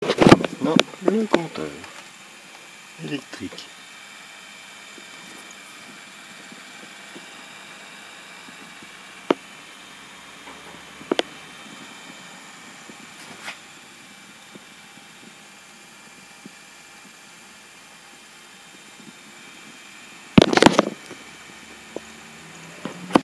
Le grésillement se répercute jusque dans le compteur électrique et toutes les prises de courant de la maison.
Alors voici le son du groupe, puis le concerto lisseur et compteur électrique.
compteur.mp3